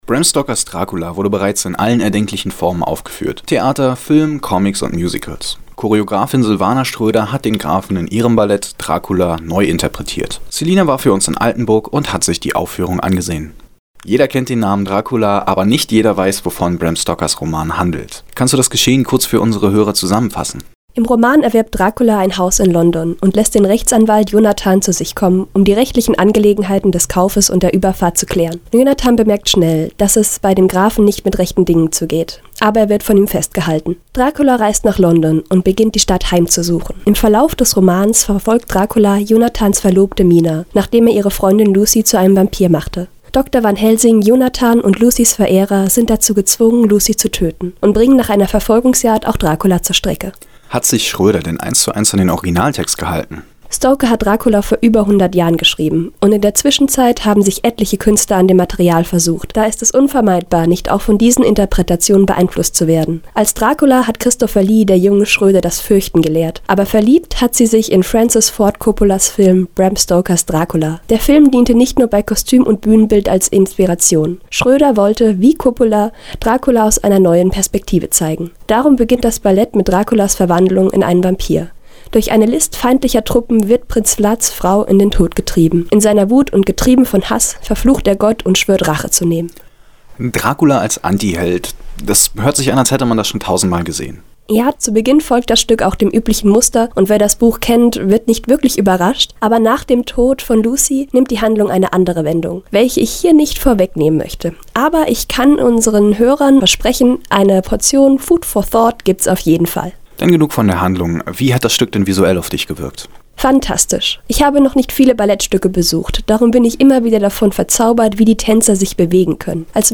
Ballettrezension: Dracula